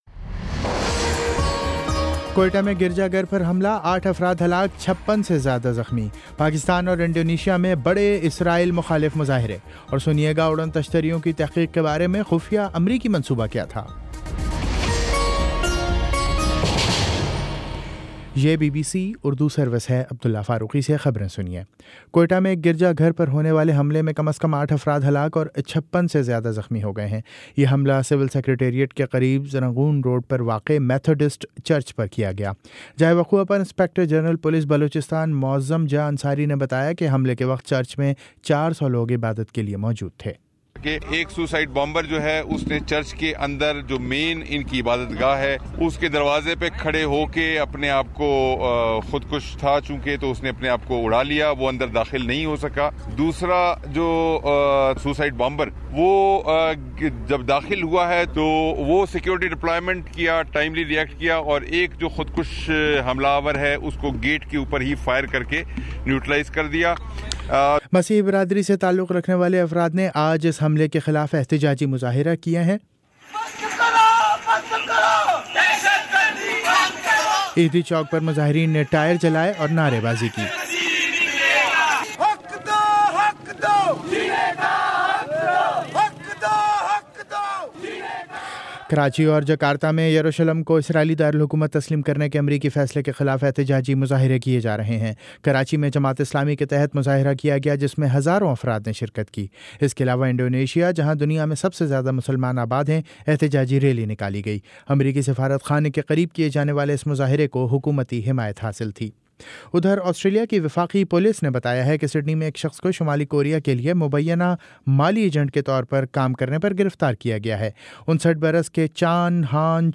دسمبر 17 : شام سات بجے کا نیوز بُلیٹن